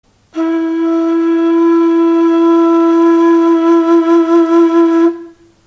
Одиночный звук курая: башкирская и татарская флейта